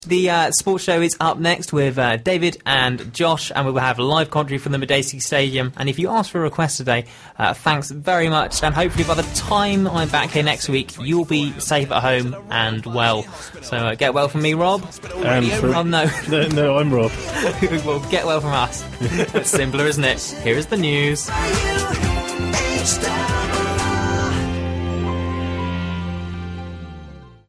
Taken from my Hospital Radio Reading Show. Everything was running perfectly, I was set to hit the news jingle exactly on time when all of a sudden I forget something rather important...